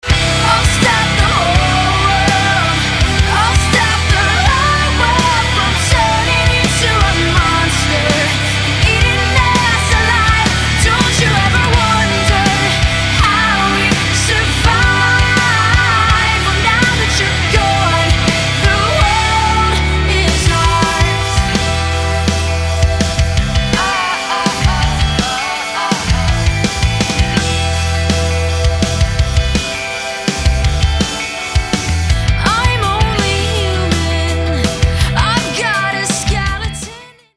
rocková skupina